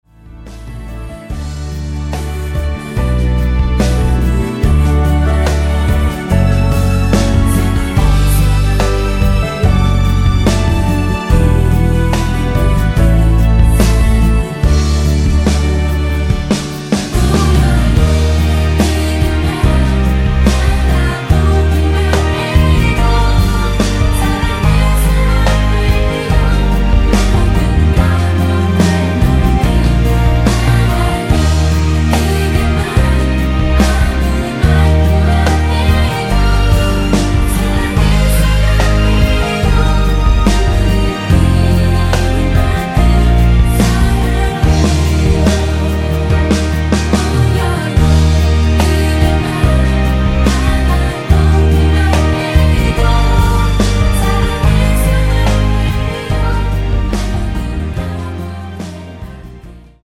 코러스 포함된 MR 입니다.
Db
◈ 곡명 옆 (-1)은 반음 내림, (+1)은 반음 올림 입니다.
앞부분30초, 뒷부분30초씩 편집해서 올려 드리고 있습니다.